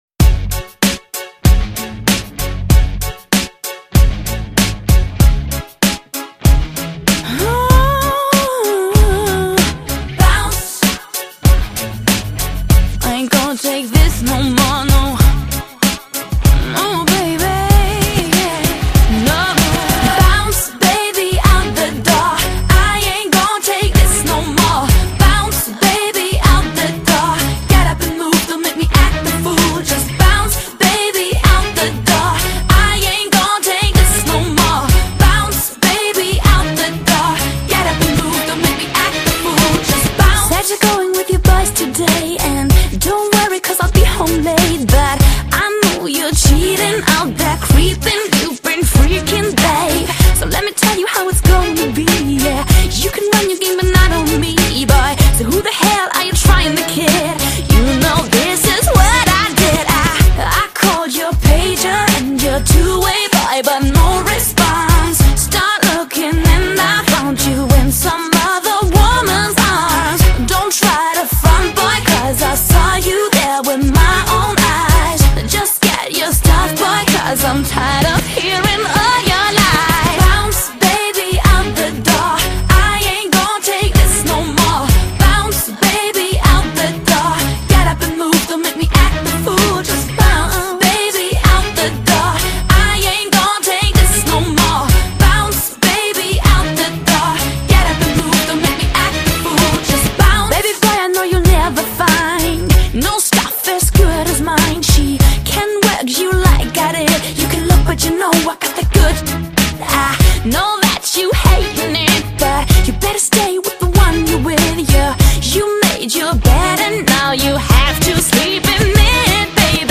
唱片类型：爵士乡村
旋侓節奏都讓人耳目一新
潮曲R&B 鮮明的節奏  讓人想要